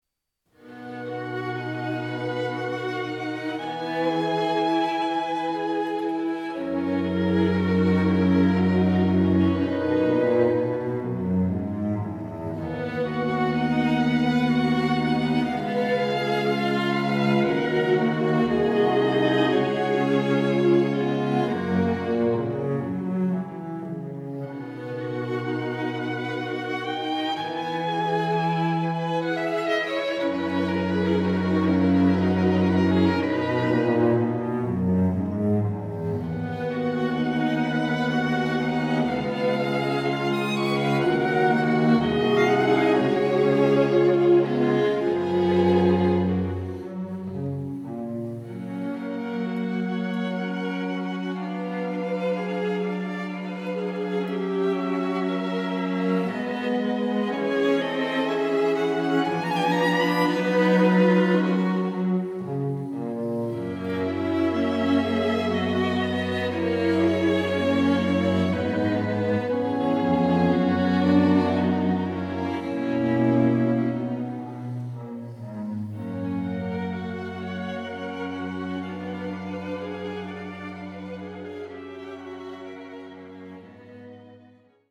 quartets
adagios